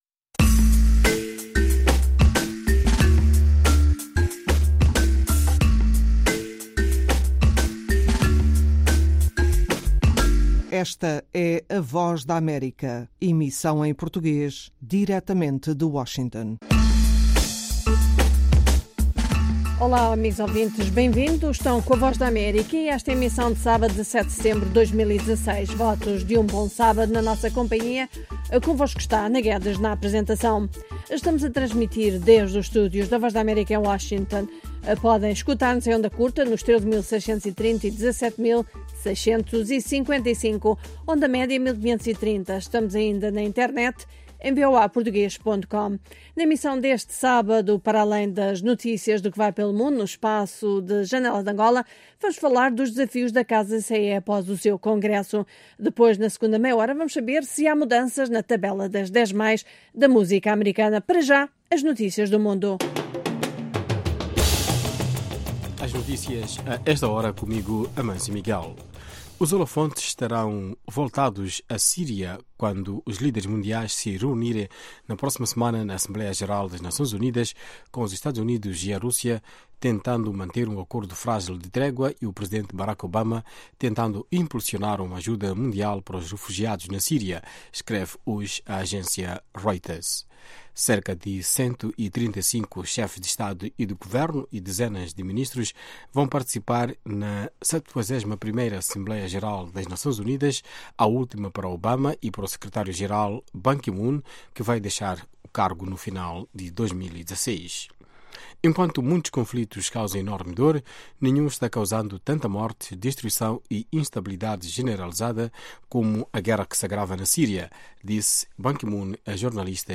Meia-hora duas vezes por Aos sábados, ouça uma mesa redonda sobre um tema dominante da política angolana, música americana e as notícias do dia.